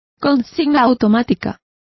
Complete with pronunciation of the translation of locker.